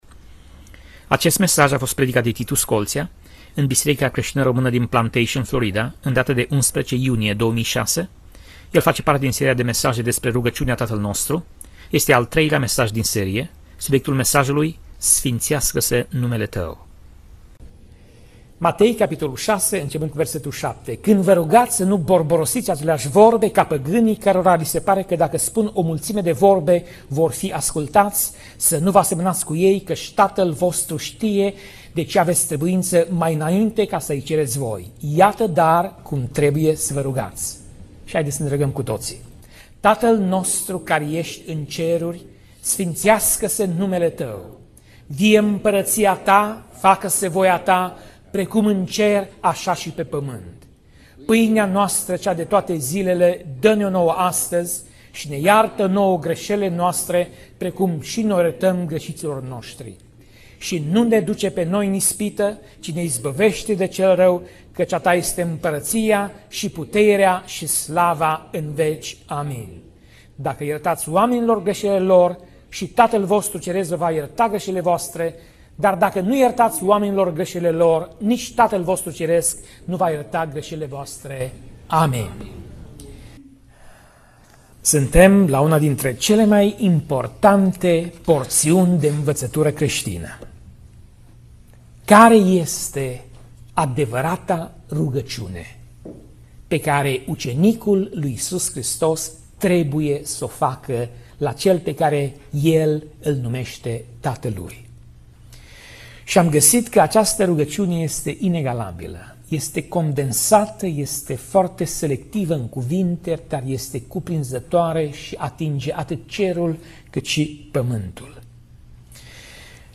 Pasaj Biblie: Matei 6:9 - Matei 6:13 Tip Mesaj: Predica